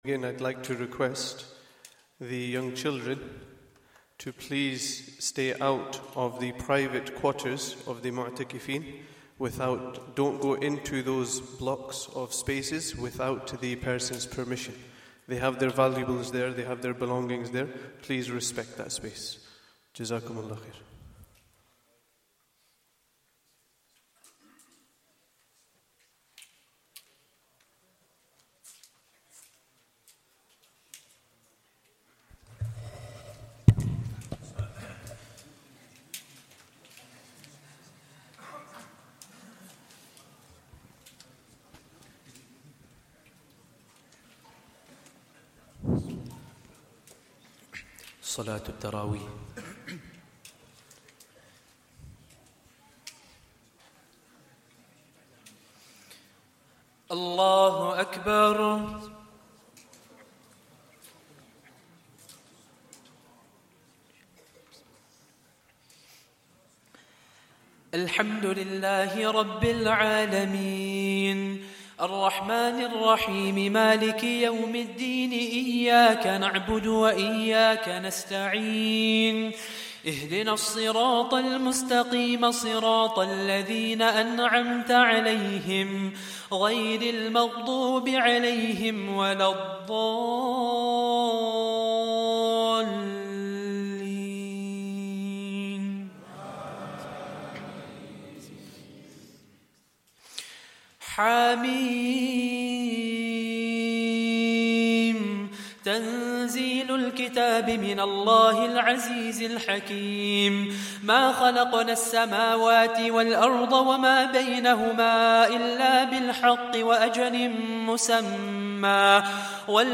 Day 21 - Taraweeh 1444 - Recital